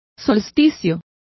Complete with pronunciation of the translation of solstices.